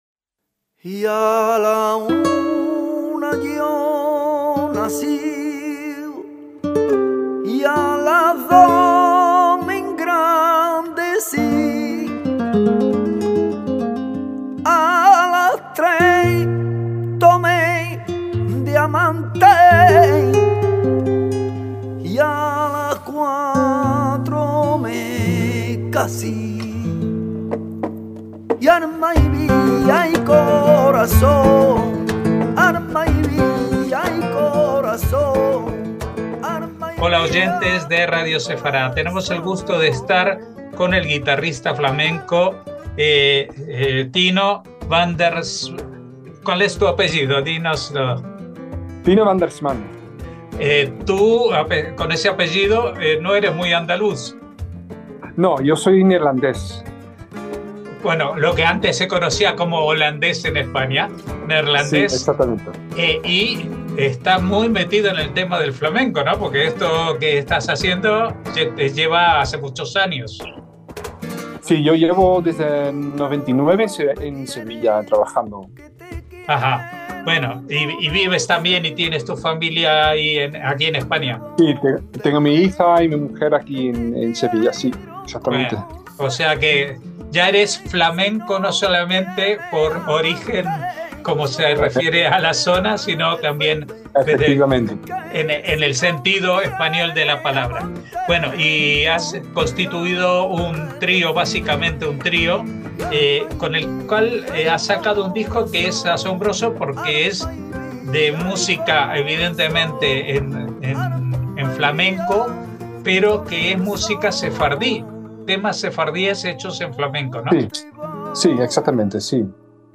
HABLEMOS DE MÚSICA